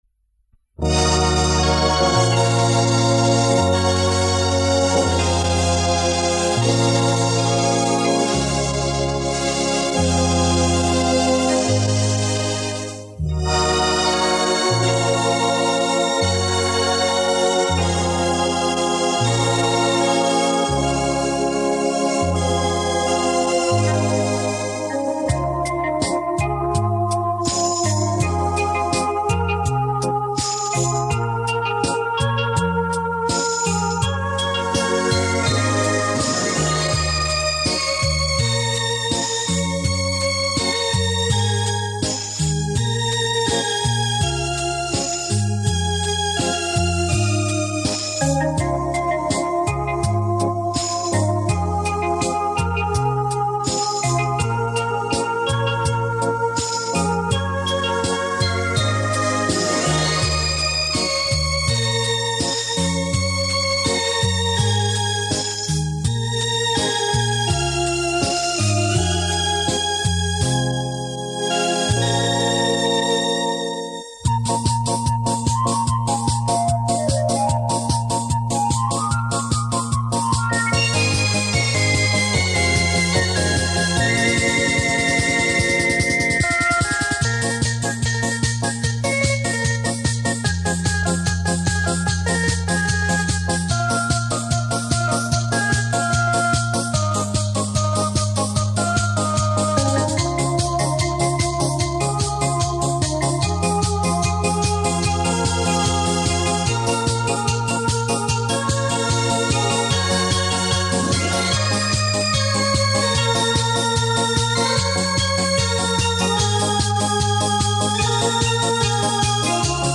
音乐介质:  磁带转WAV